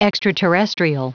Prononciation du mot extraterrestrial en anglais (fichier audio)
Prononciation du mot : extraterrestrial